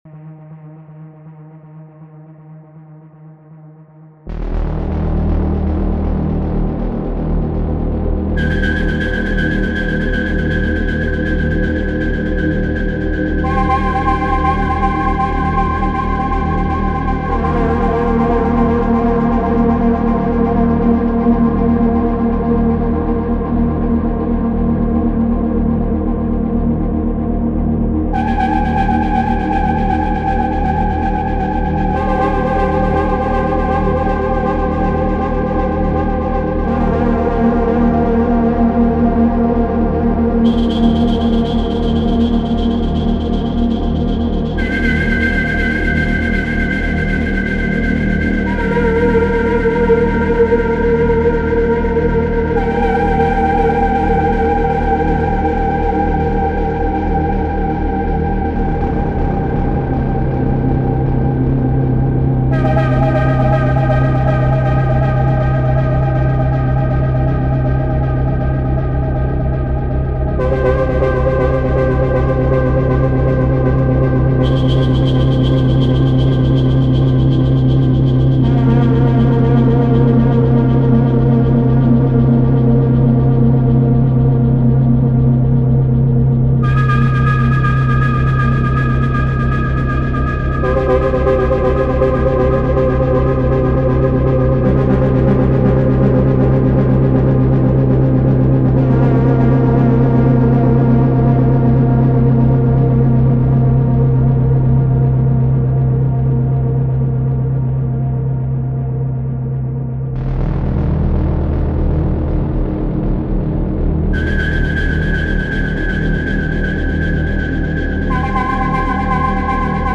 Digitone + Moogerfooger Delay & Valhalla Vintage Verb